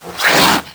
c_viper_atk2.wav